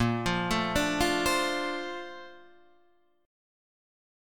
A# Major 9th